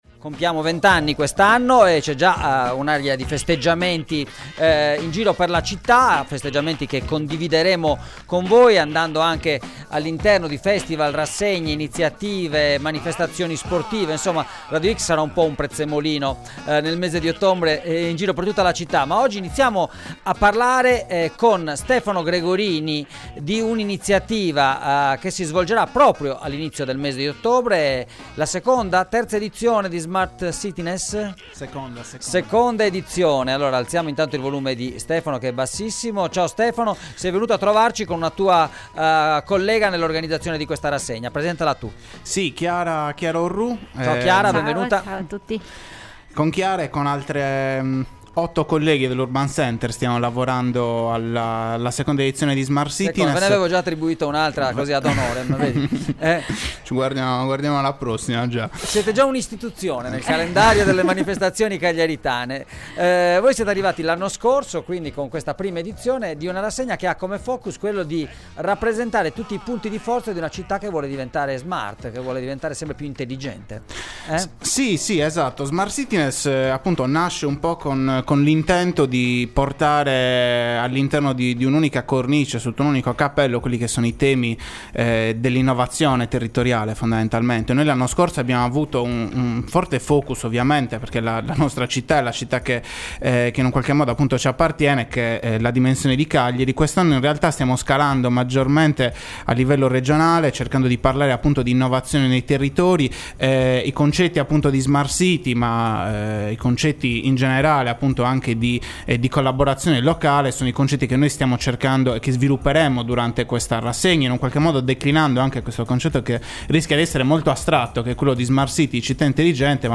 organizzatori del festival ASCOLTA L’INTERVISTA http